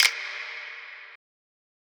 Snare (7).wav